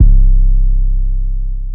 808 (Metro)_2.wav